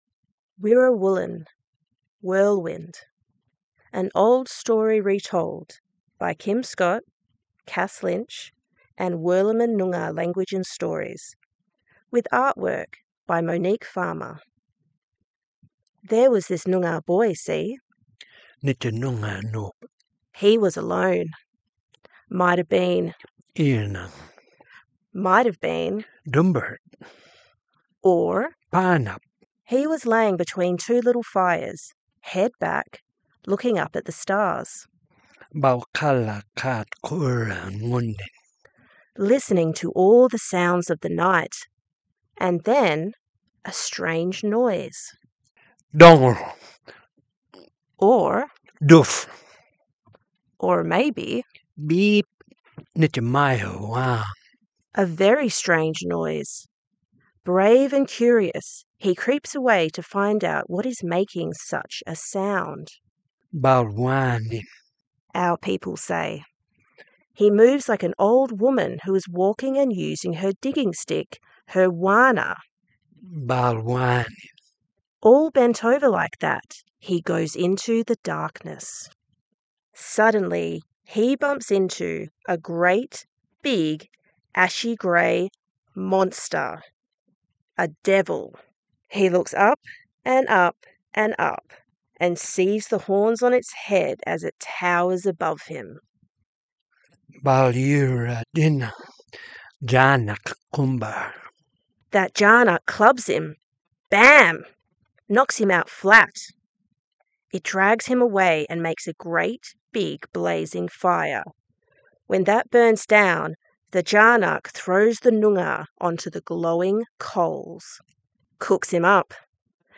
An old story retold by Kim Scott